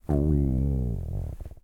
pooping_rumble.ogg